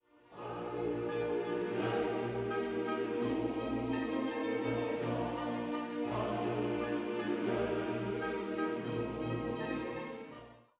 The end features soaring sopranos (high A) followed by the cadence which Wolfgang lifted for his “Regina caeli, laetare”,